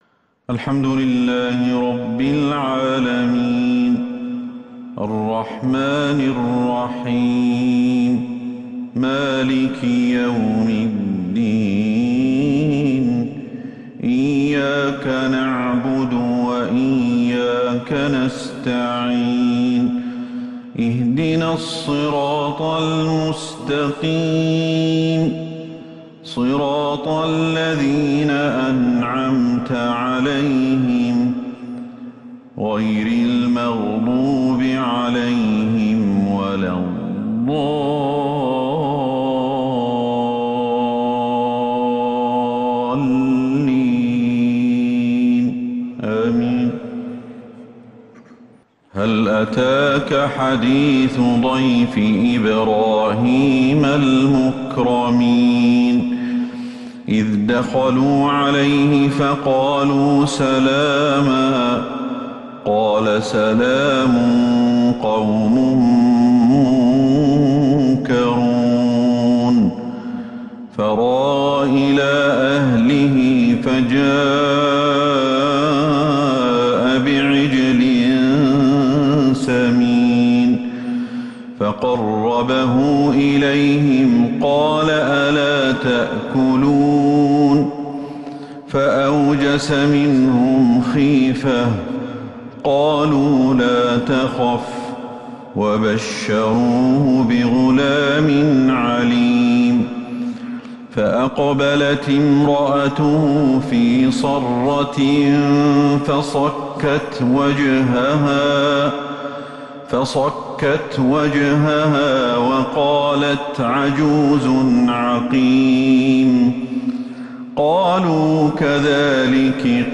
عشاء الأحد 4 ربيع الأول 1443هـ من سورة {الذاريات} > 1443 هـ > الفروض - تلاوات الشيخ أحمد الحذيفي